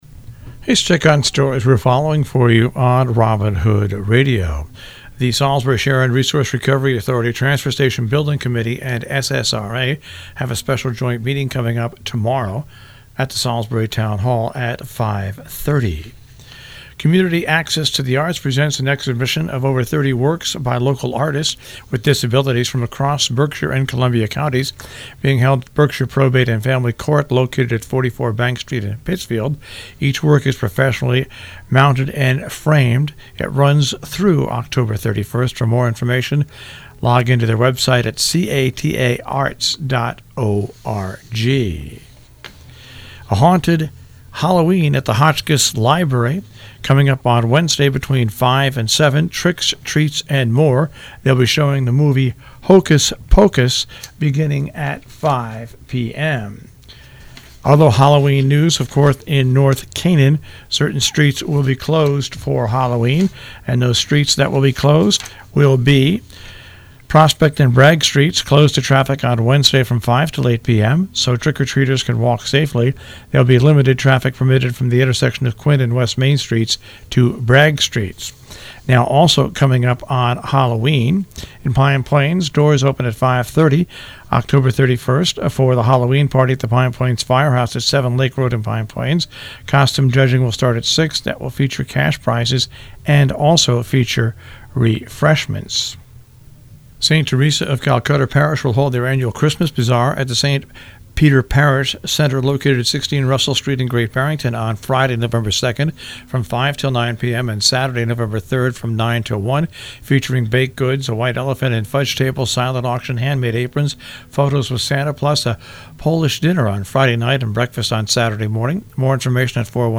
covers news and events in the Tri-State Area on The Breakfast Club on Robin Hood Radio